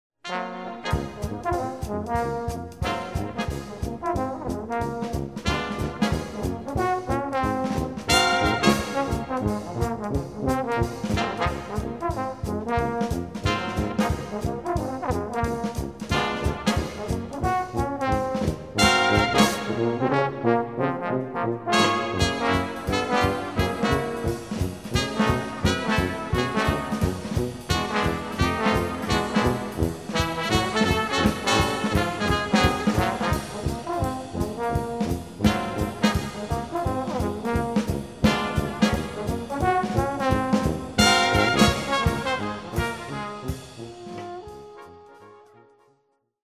Swing, Jazz, Dixie mp3's: